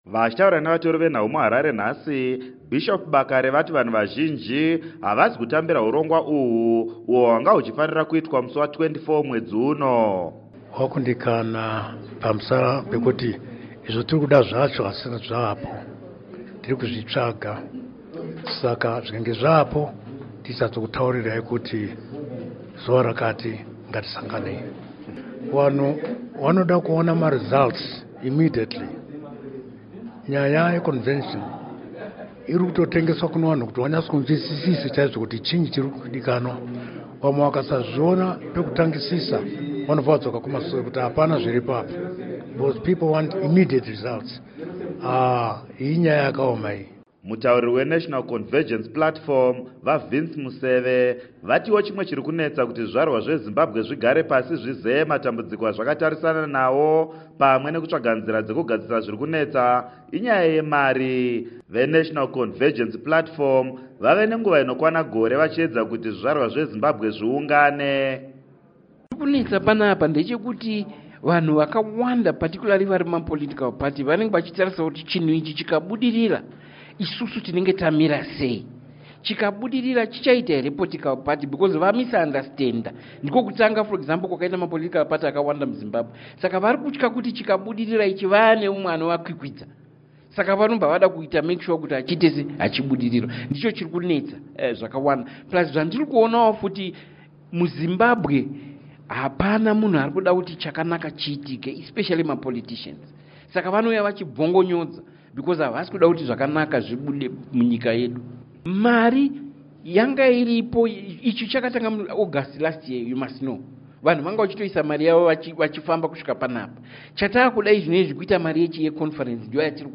Vachitaura nevatori venhau mu Harare, Bishop Bakare vati vanhu vazhinji havasi kutambira hurongwa uhwu uhwo hwanga huchifanira kuitwa musi wa 24 Gumiguru, 2015.